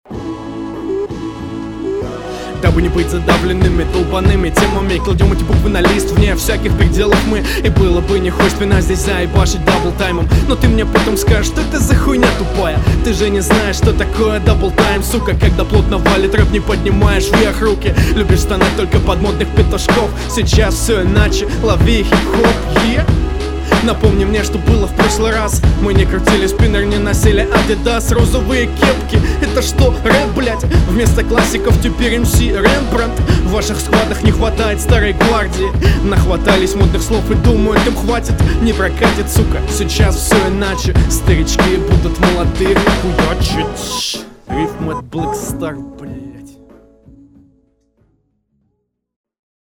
Слышна неуверенность, и в подаче, и в ритмике. Перебежки слабые в начале, какой уж там даблтайм.